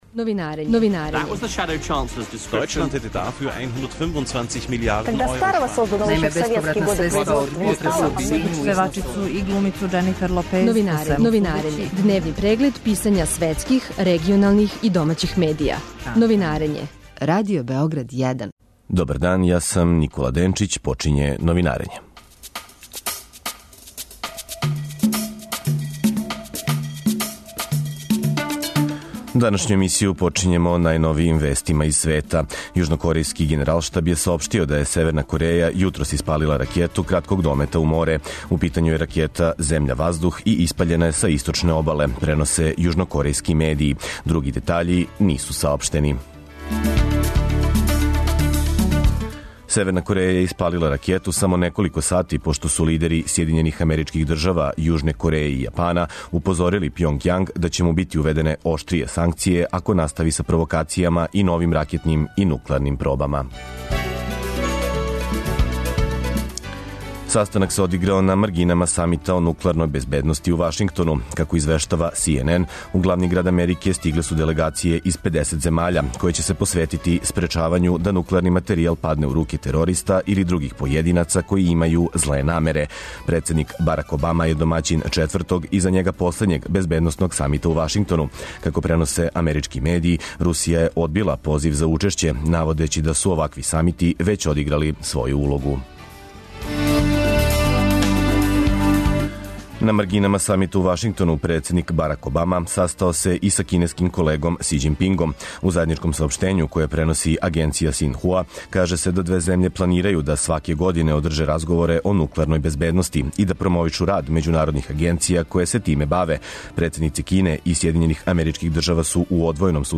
О последицама одлуке Хашког суда за Радио Београд 1 говоре аналитичари из Загреба, Сарајева и Бањалуке.